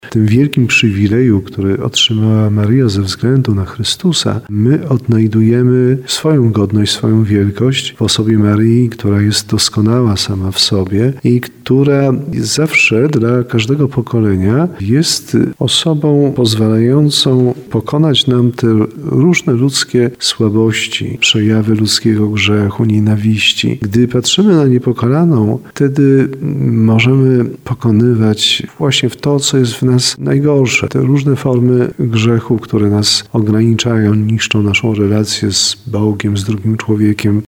To święto ujawnia godność każdego człowieka – tak o uroczystości Niepokalanego Poczęcia Najświętszej Maryi Panny mówi biskup tarnowski Andrzej Jeż.